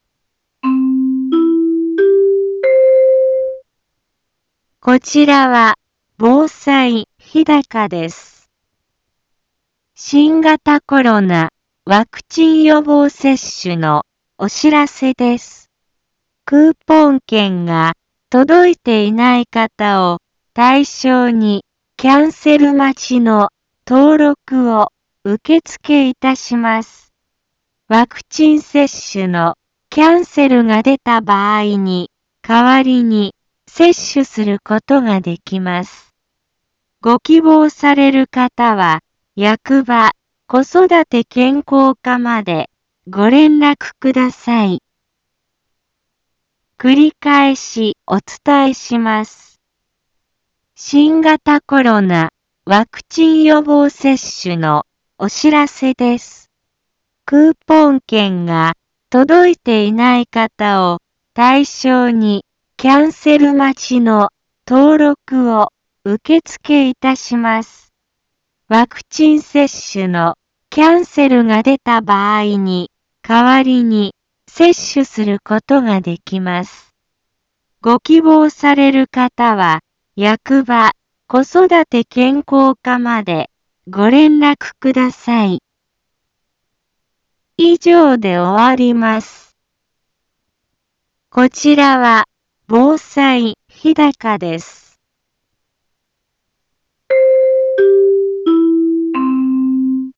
Back Home 一般放送情報 音声放送 再生 一般放送情報 登録日時：2021-07-21 15:03:33 タイトル：新型コロナワクチン予防接種のお知らせ インフォメーション：こちらは防災日高です。 新型コロナワクチン予防接種のお知らせです。